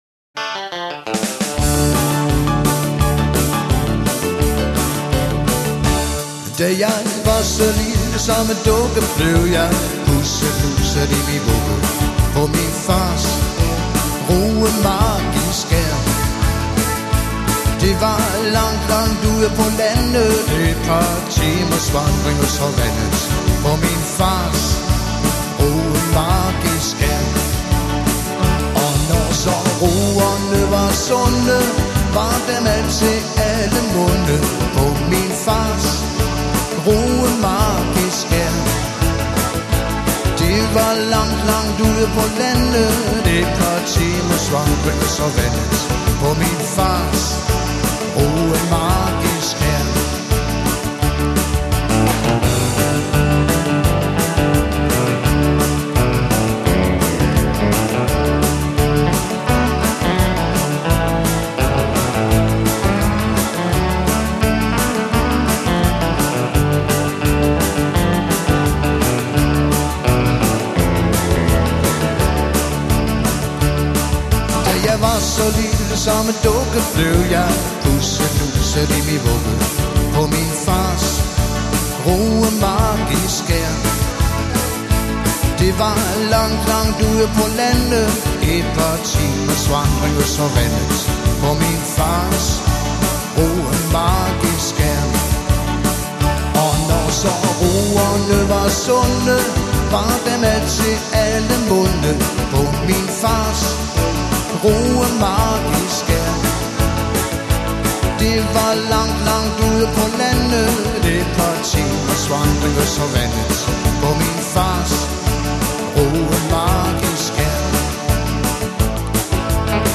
Professionelt danse og hyggemusik til alle aldersgrupper.